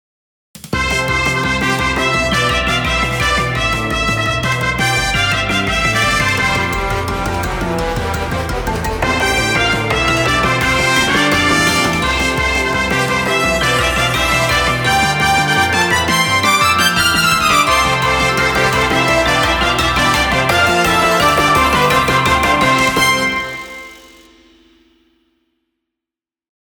どんちゃんわいわい、無法地帯。